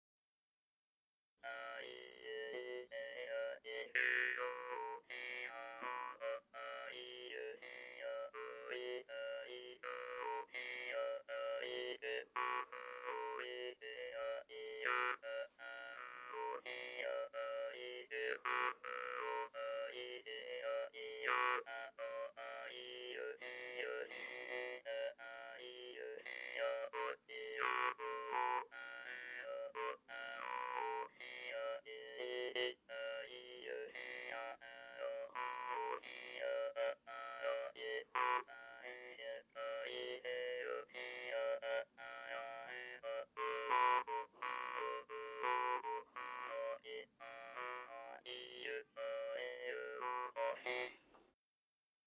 brass mouth harp solo courting music 800KB
Track 30 Hmong mouth harp.mp3